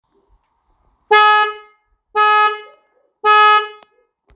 Fanfáry 12V 2-tonový
Jednotlivé tony pokud se použije pouze jedna nebo druhá fanfára.